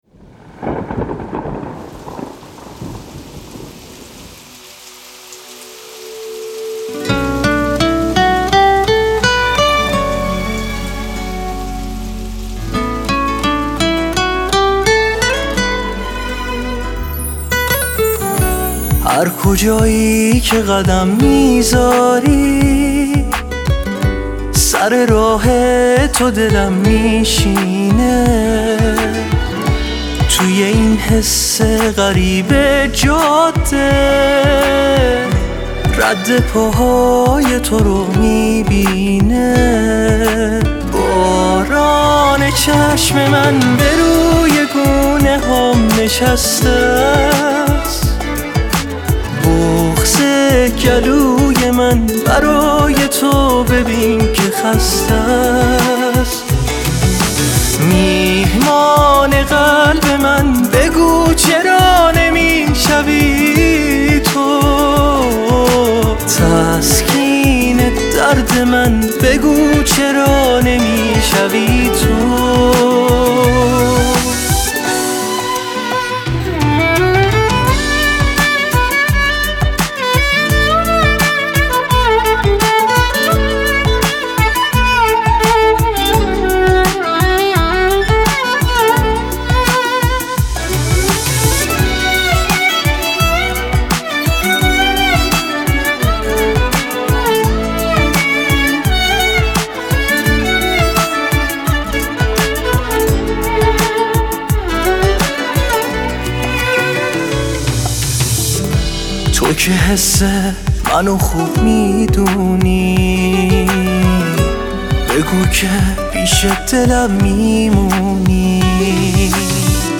بخش دانلود آهنگ غمگین آرشیو